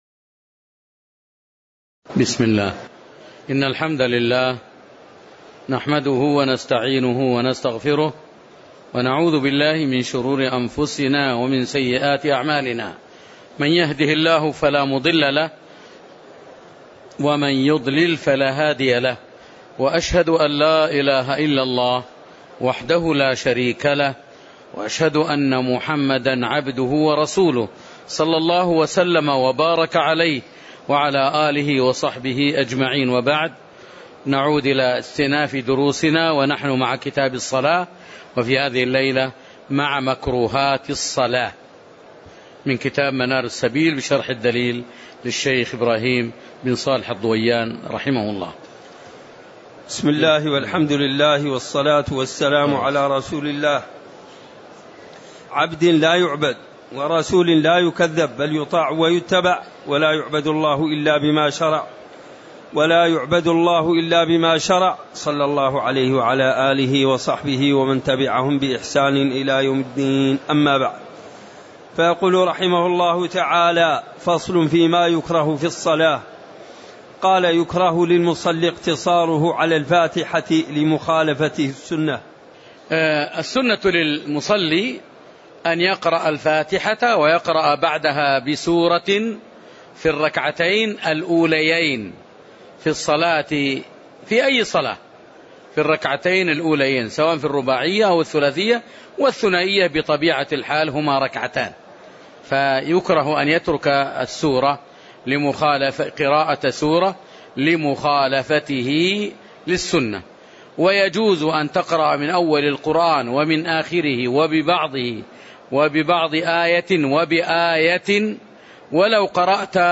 تاريخ النشر ١٦ ذو الحجة ١٤٣٨ هـ المكان: المسجد النبوي الشيخ